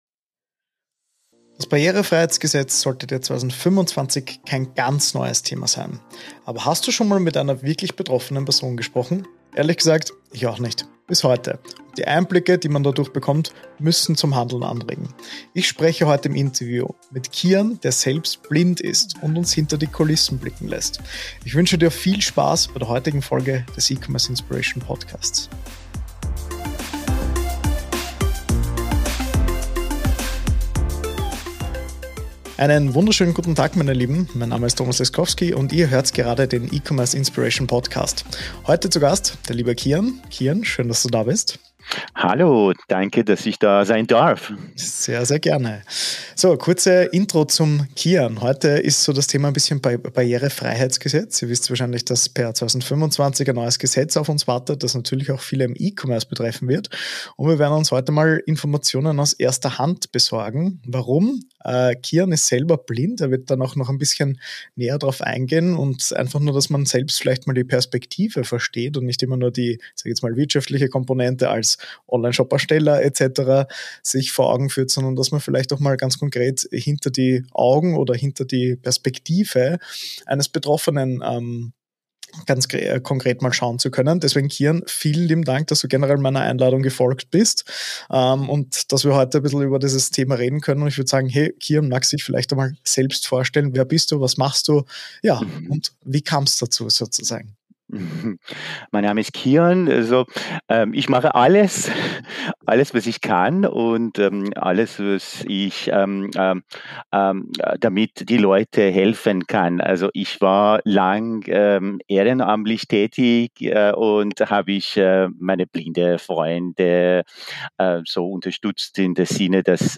#038: Interview mit einem Blinden: Barrierefrei bis 2025 durch diese Tipps.